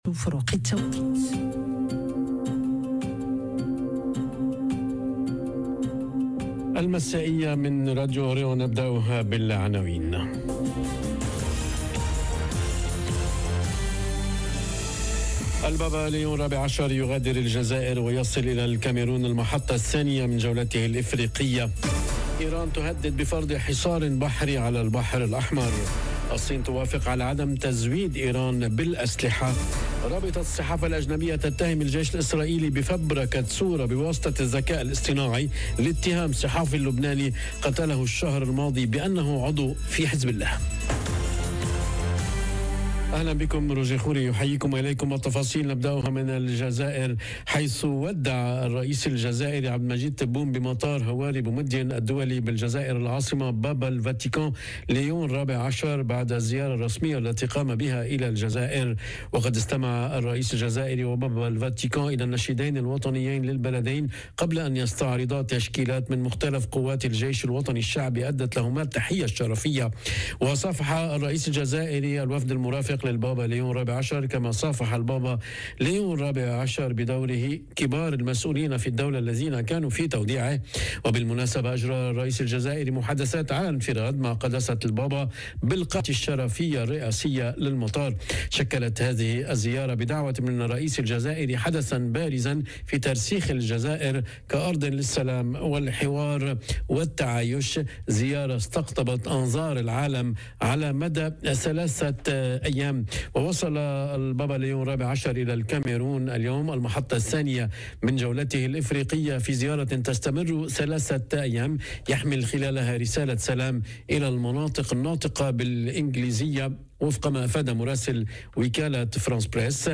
نشرة المساء.. إيران تهدد بحصار بحري على البحر الأحمر - Radio ORIENT، إذاعة الشرق من باريس